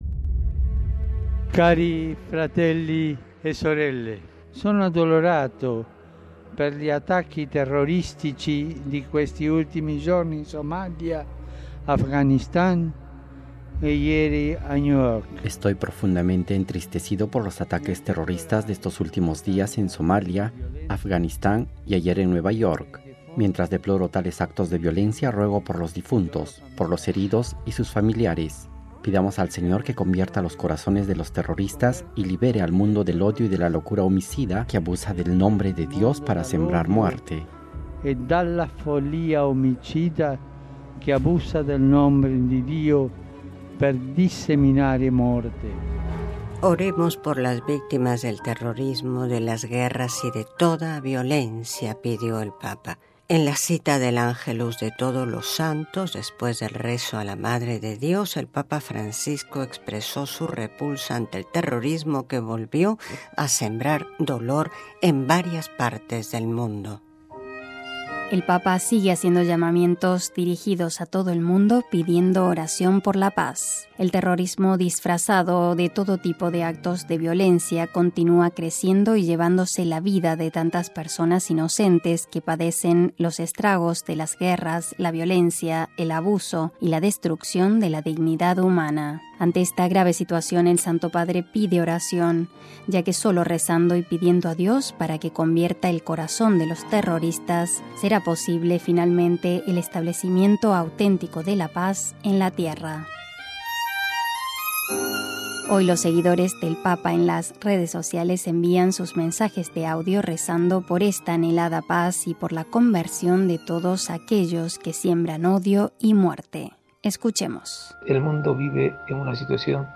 Hoy los seguidores del Papa en las redes sociales envían sus mensajes de audio rezando por la tan anhelada Paz y por la conversión de todos aquellos que siembran odio y muerte.
En diálogo con nuestros oyentes escuchamos la voz del Papa Francisco durante su homilía en Ciudad Juarez, en su viaje apostólico a México en febrero de 2016, hablando del poder de la conversión y la necesidad de pedir a Dios "el don de las lágrimas", para no ser indiferentes ante el sufrimiento ajeno.